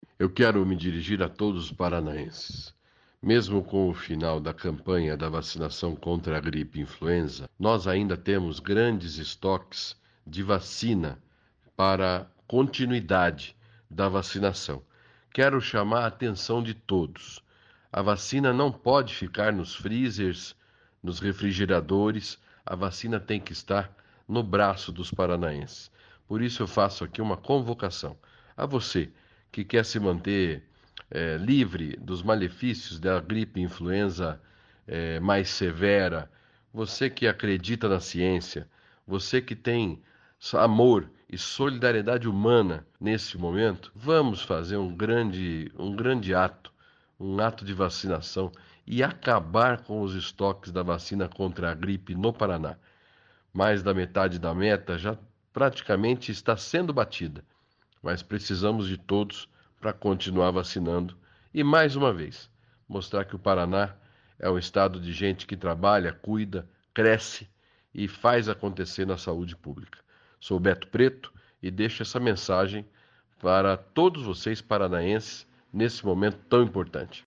Sonora do Secretário da Saúde, Beto Preto, sobre a vacinação contra a gripe